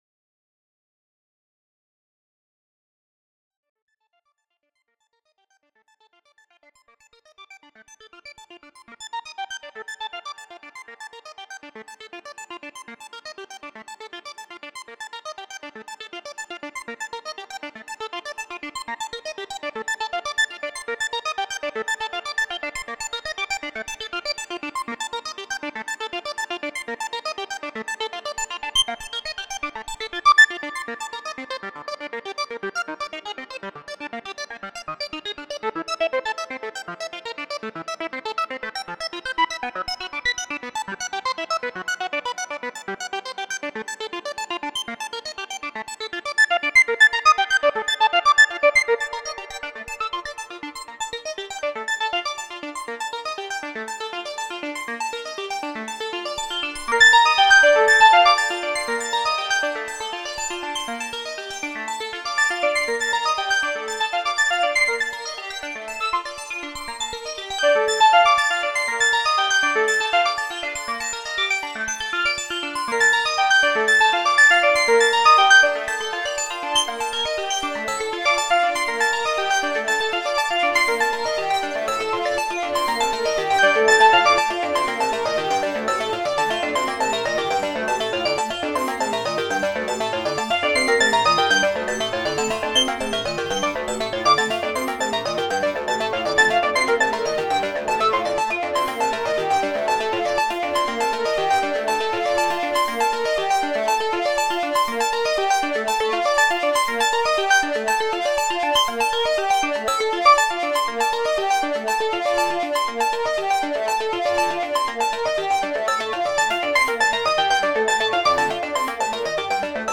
One FM Tone track, with another FM Tone layer - a simple jam with slow LFOs and some live tweaking.